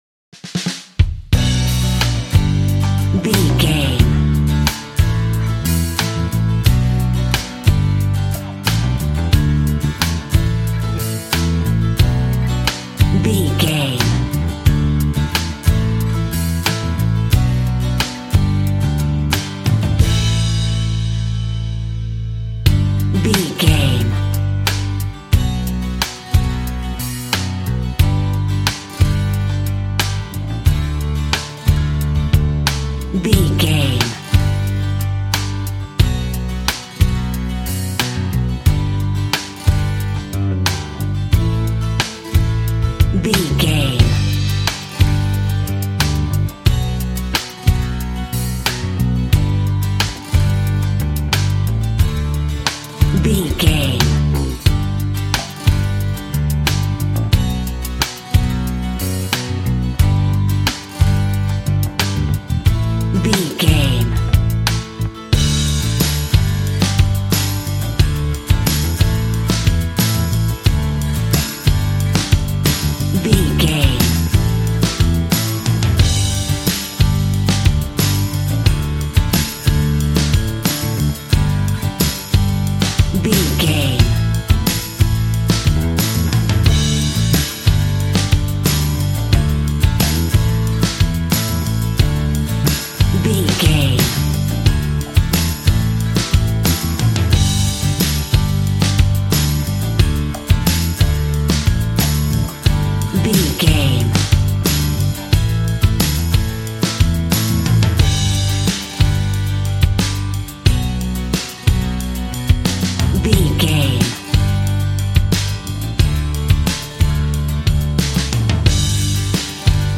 Ionian/Major
D
romantic
happy
bass guitar
drums